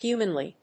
音節hu･man･ly発音記号・読み方hjúːmənli
発音記号
• / ˈhjuːmənli(米国英語)